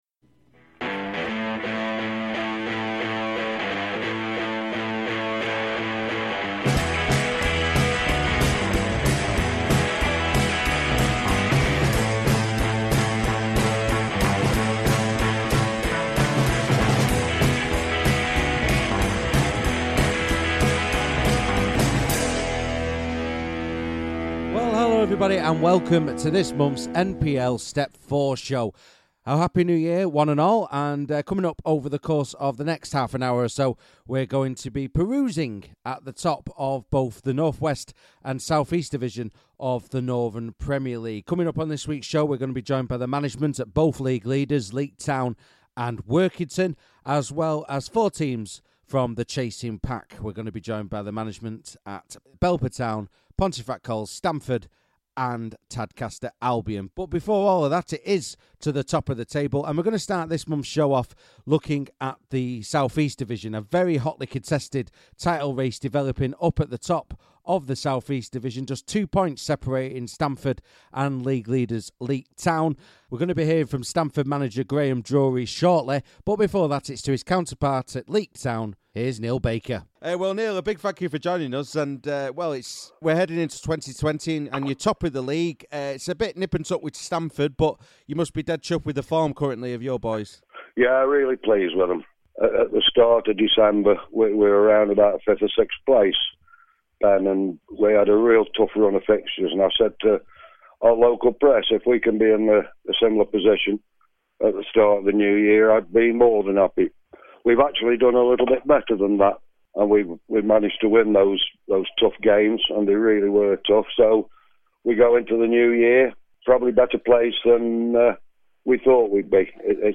On the show, we're joined by the Management from Workington, Leek Town, Stamford, Belper Town, Pontefract Cols and Tadcaster Albion.